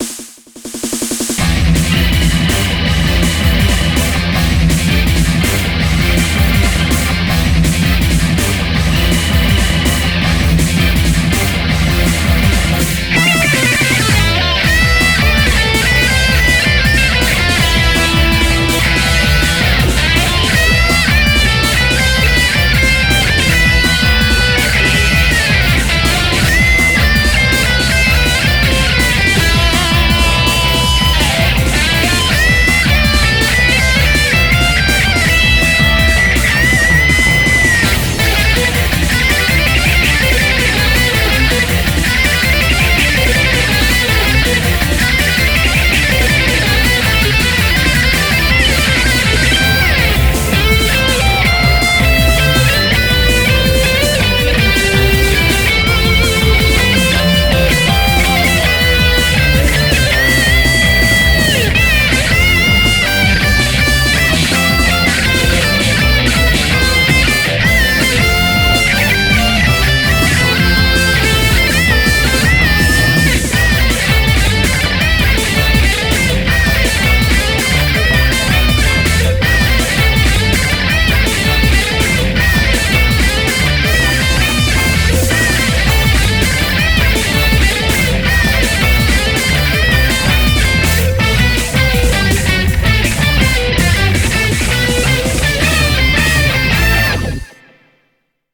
BPM163
Audio QualityMusic Cut